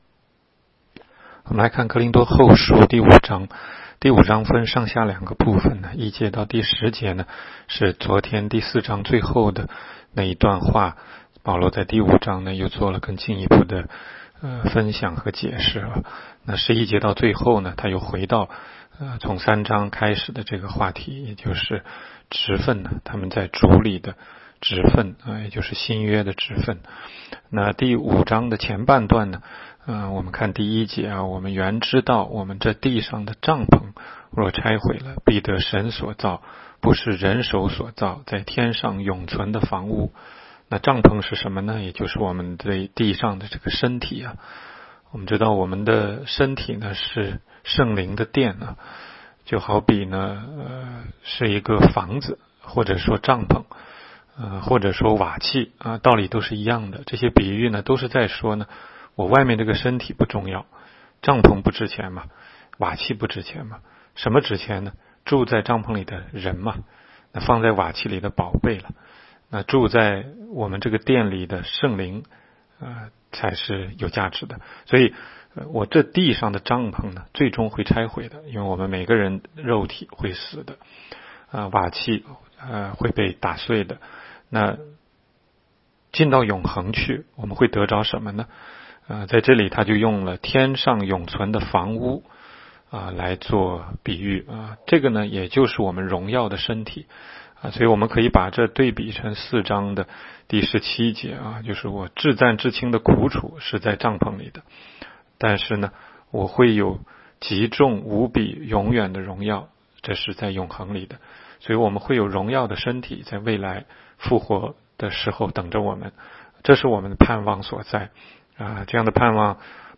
16街讲道录音 - 每日读经-《哥林多后书》5章
每日读经